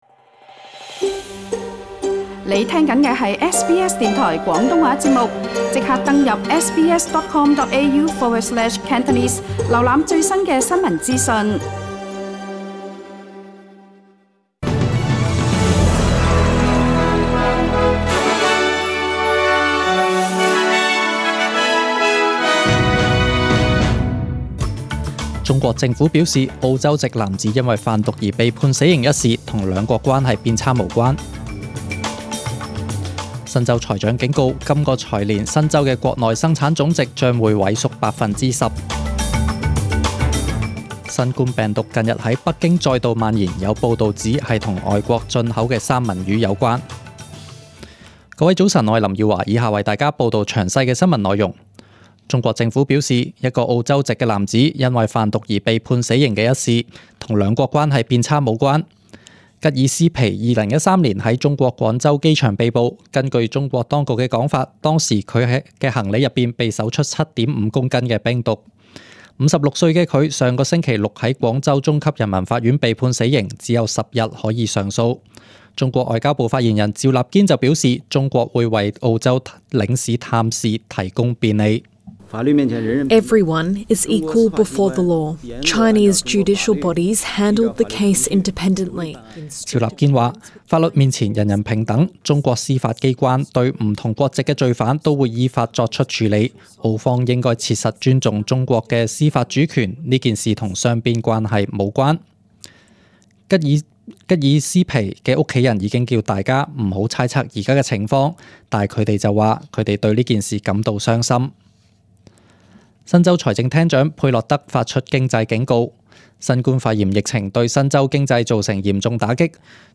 SBS中文新闻 （六月十六日）
请收听本台为大家准备的详尽早晨新闻。